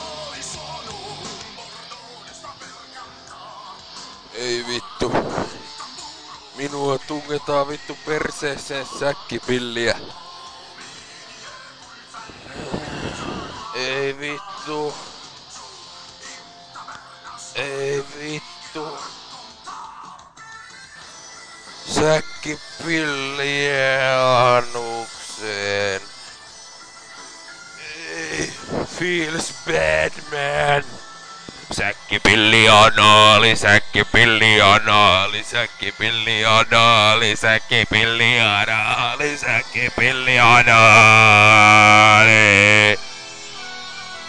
(365.48 KB säkkipillianaali.mp3)